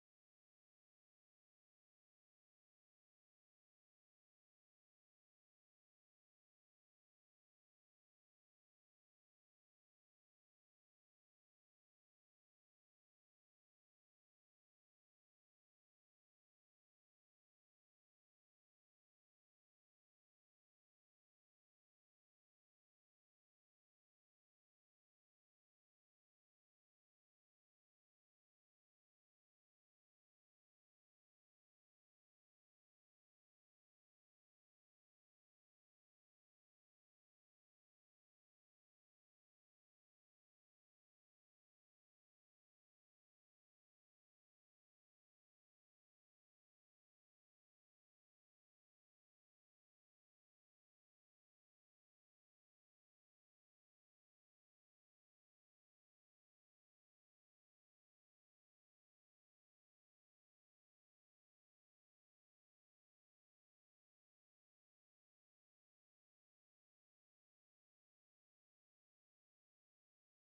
Bandari 4/4 182 بندري
Bandari-4-4-182.mp3